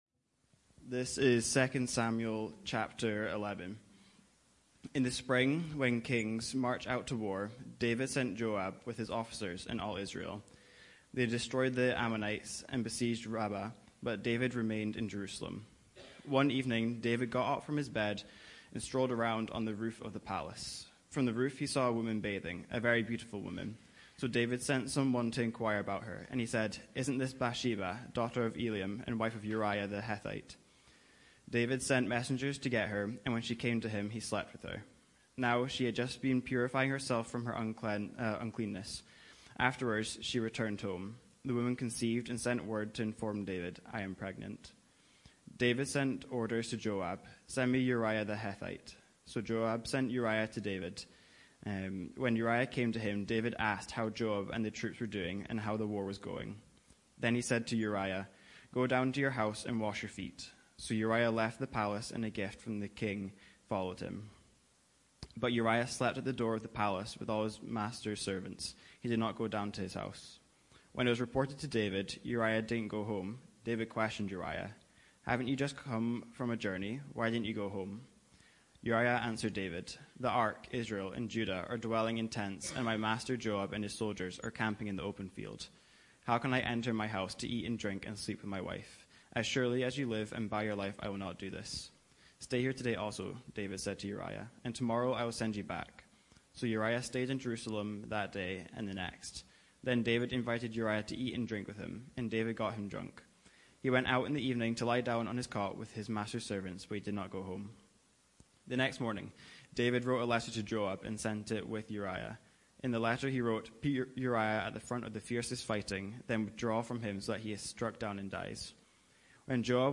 Weekly sermons from HCC